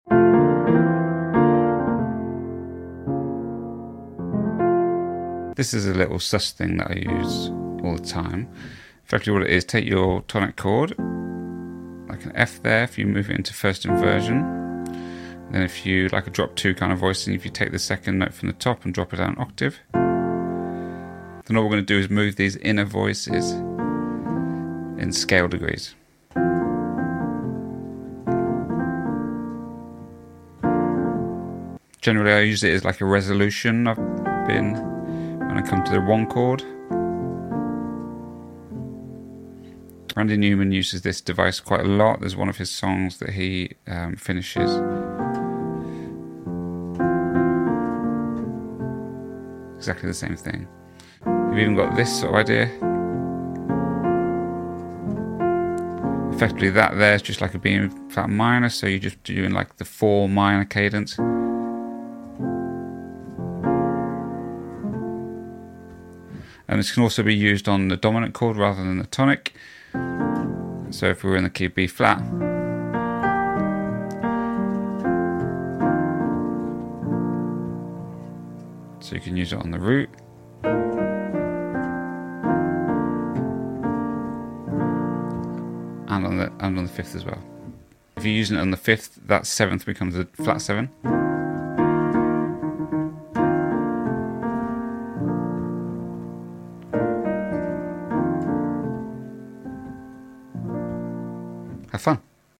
Here’s a little piano sus chord thing I like to use a lot.